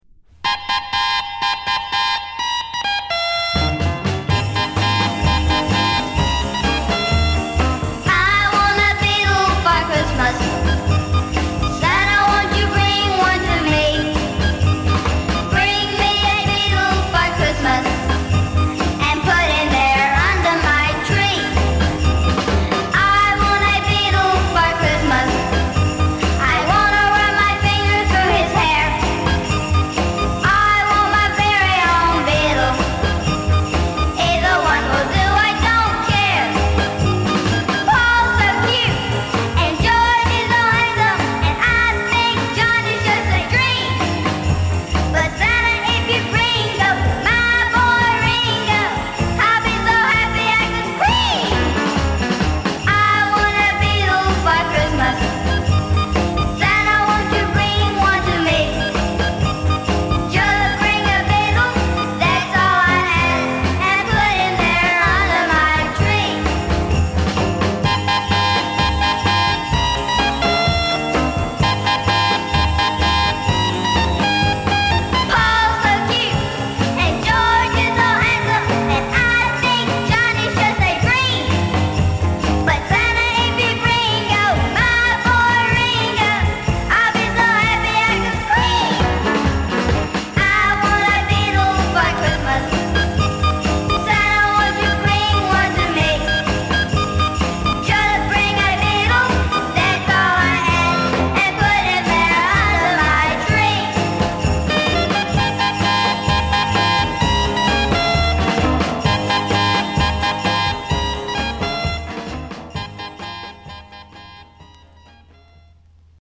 パンチの効いたヴォーカルがサウンドに清々しさと活気を与えている。